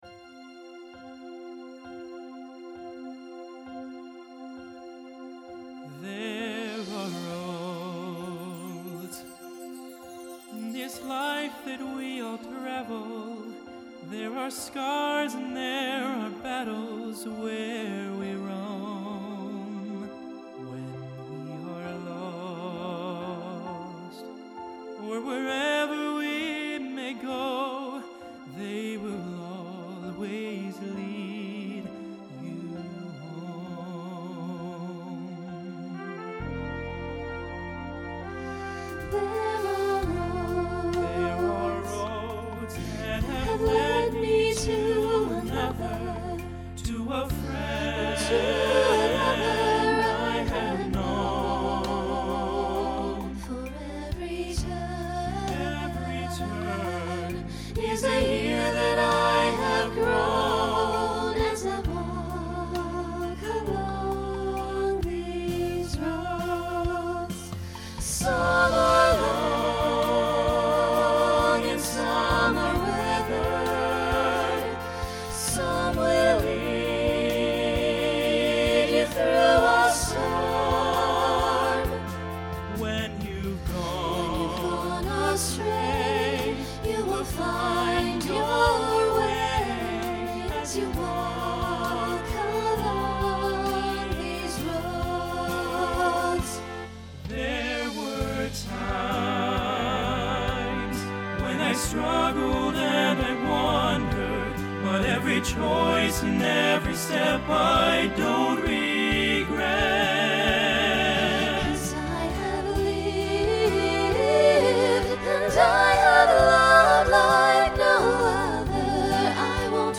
Pop/Dance Instrumental combo
Ballad , Solo Feature Voicing SATB